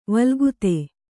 ♪ valgute